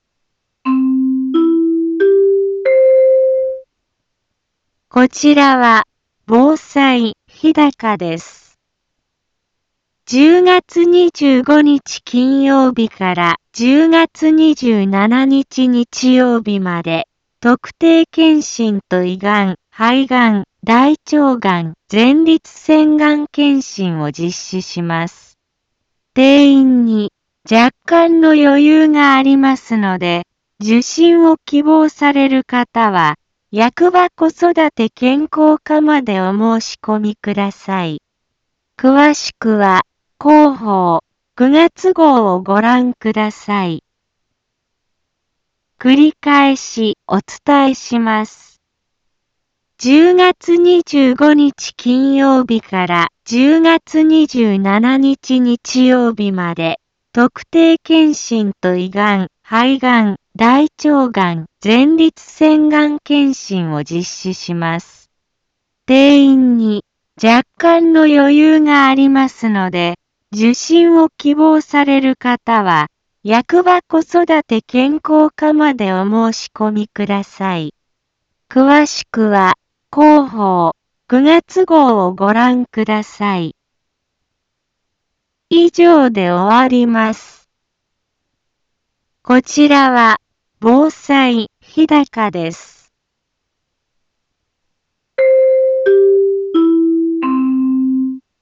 一般放送情報
Back Home 一般放送情報 音声放送 再生 一般放送情報 登録日時：2024-09-18 10:03:28 タイトル：特定検診・がん検診のお知らせ インフォメーション： 10月25日金曜日から10月27日日曜日まで、特定検診と胃がん・肺がん・大腸がん・前立腺がん検診を実施します。